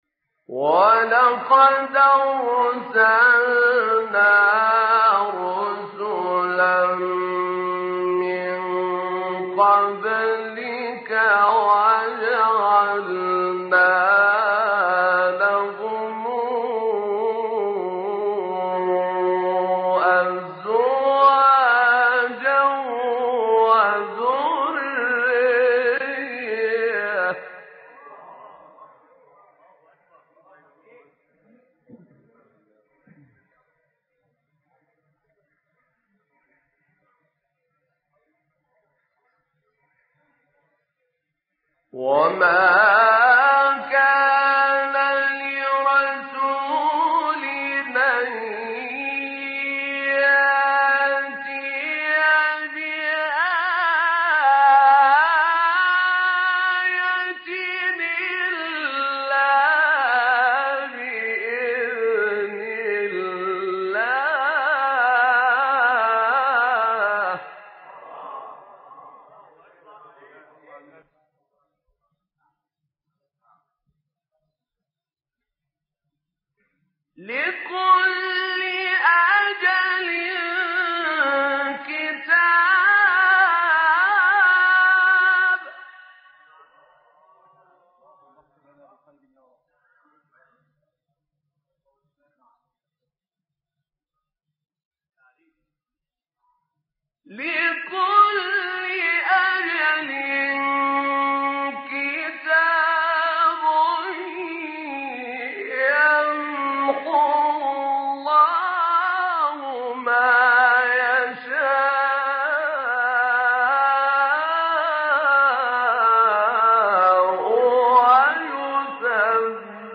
تلاوت بخشی از سوره رعد با صدای طاروتی | نغمات قرآن | دانلود تلاوت قرآن